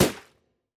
Ballon (1).wav